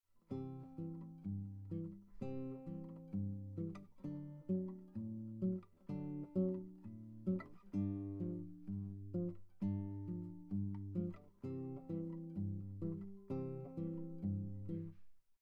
This fingerpicking style is characterized by a repeated bass pattern, while playing chords and melody at the same time.
For this pattern, we now include a single note melody at the top of the chords and bass notes.
Travis picking pattern 3: Adding a melody note